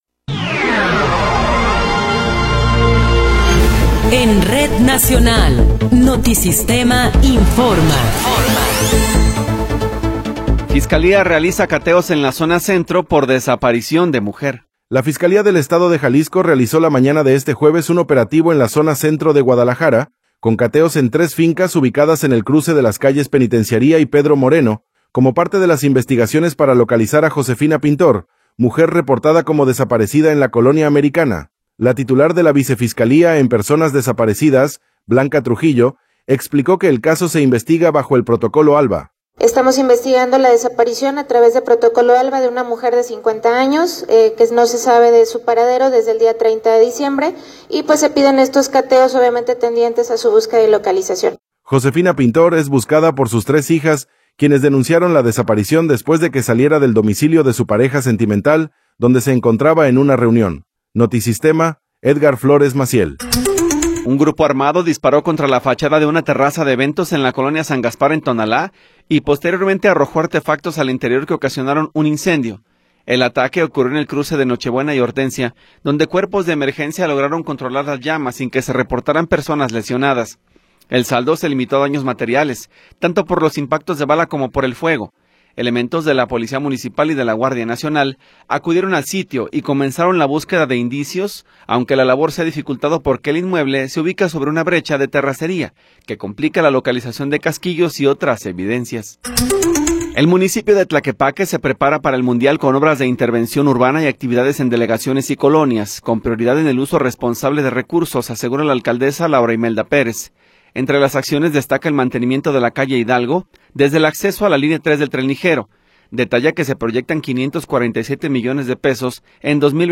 Noticiero 10 hrs. – 15 de Enero de 2026